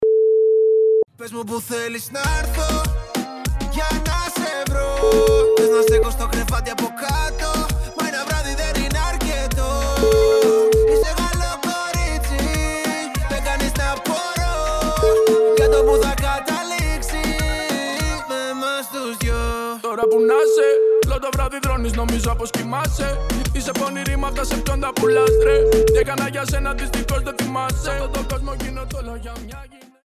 Best (T)raps